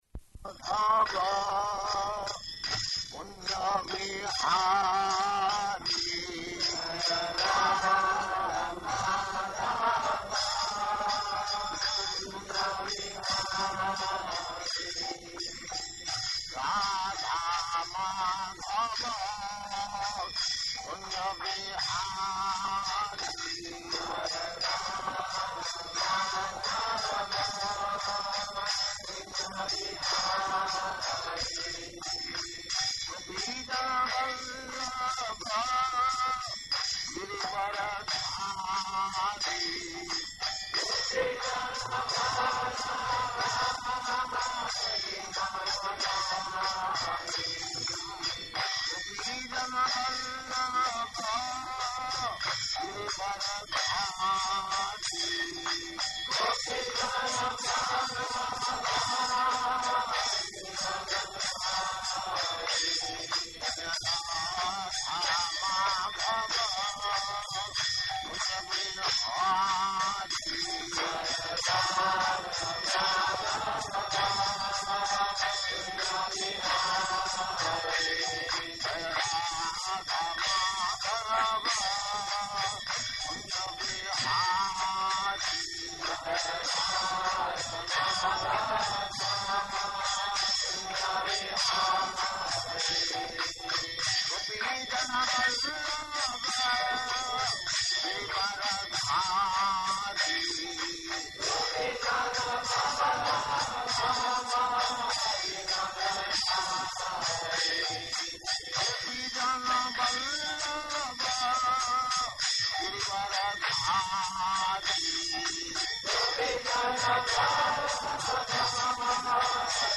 Śrīmad-Bhāgavatam 1.1.2 --:-- --:-- Type: Srimad-Bhagavatam Dated: August 16th 1971 Location: London Audio file: 710816SB-LONDON.mp3 Prabhupāda: [Sings Jaya Rādhā-Mādhava ] [ prema-dhvani ] Thank you very much.
[devotees offer obeisances] Prabhupāda: Get some books, more books.